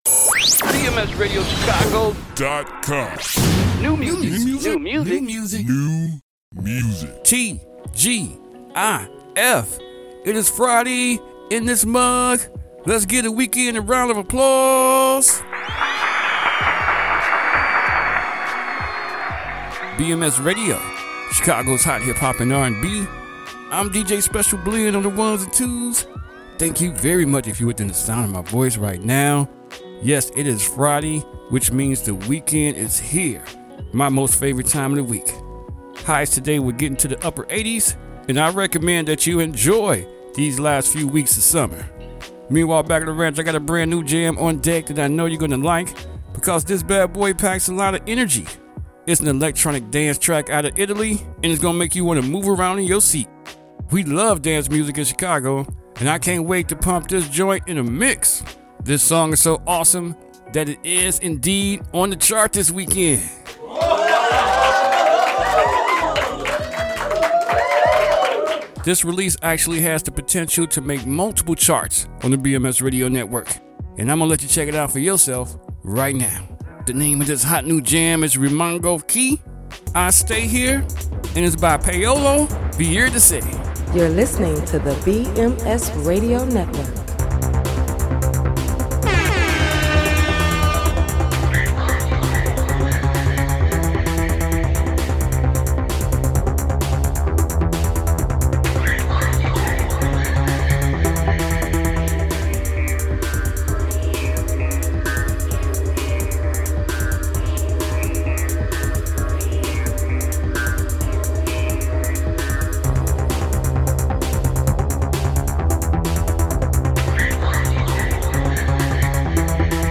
This electronic record will sound great on the mixshow.